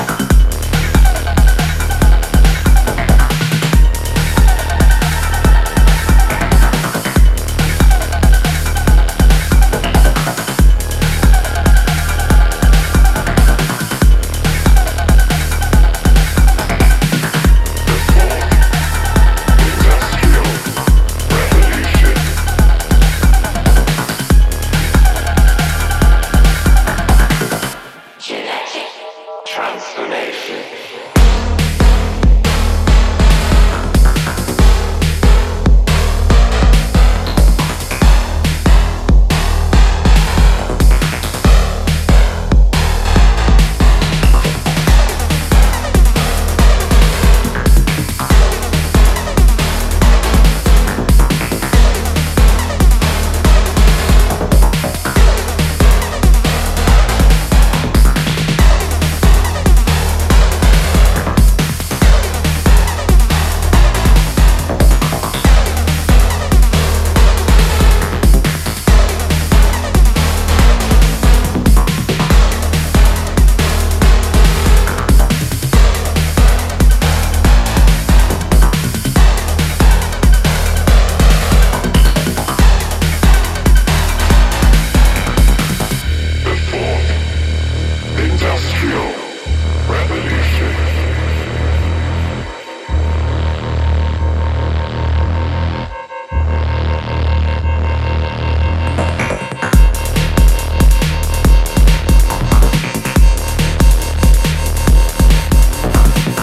Four solid electro cuts dominate